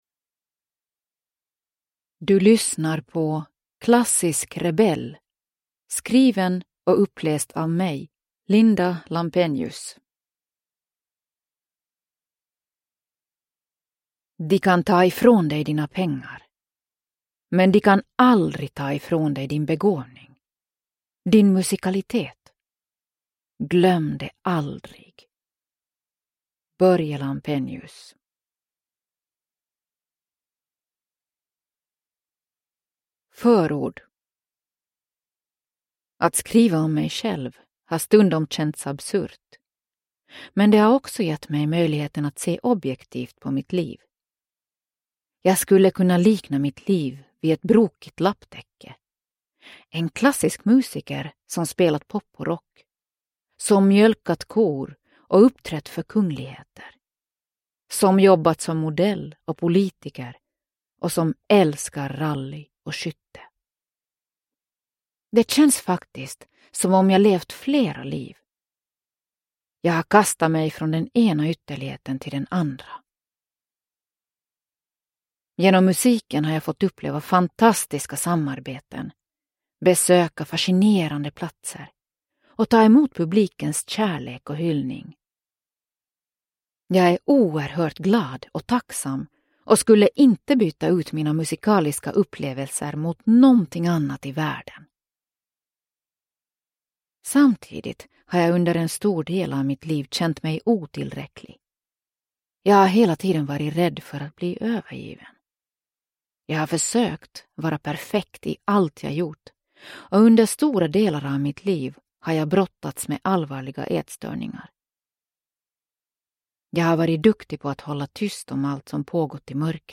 Klassisk rebell – Ljudbok – Laddas ner